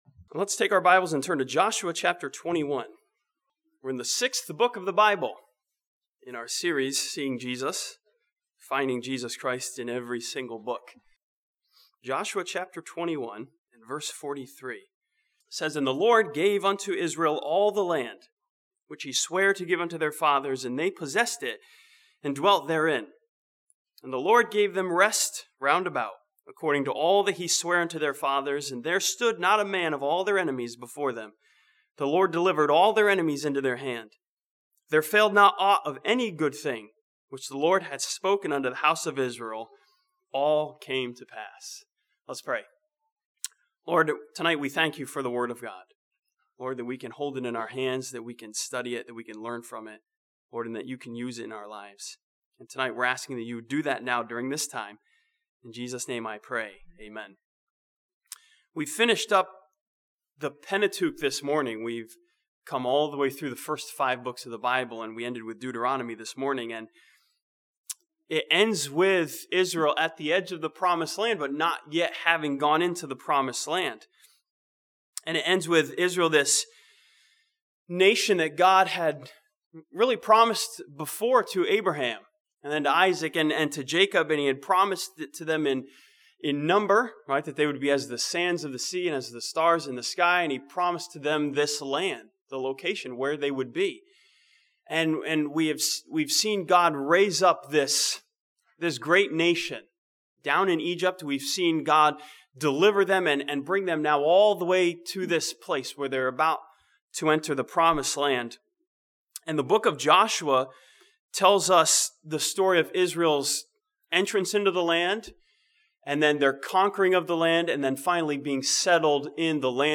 This sermon from Joshua 21 continues seeing Jesus as the rest that is given to Christians who trust in Jesus.